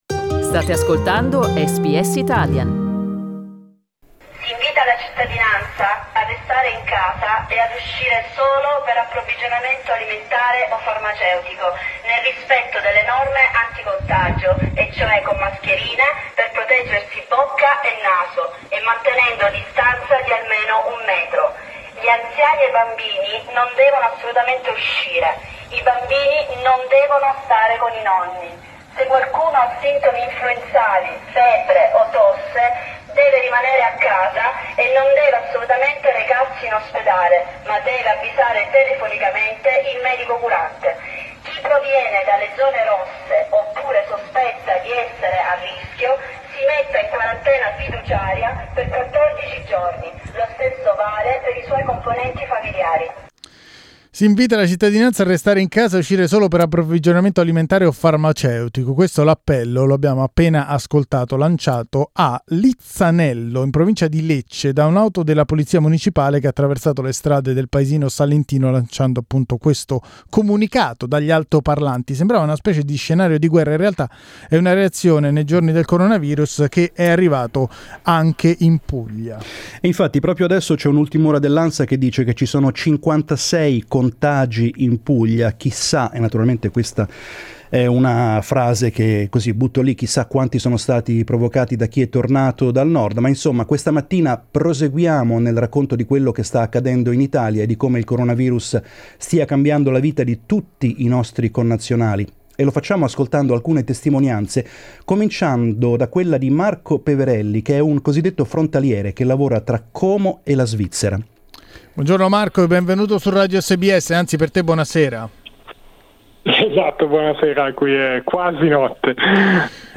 Ne abbiamo parlato questa mattina in diretta con i nostri ascoltatori, con un "frontaliere" di Como che lavora in Svizzera e con un musicista italiano appena giunto in Australia.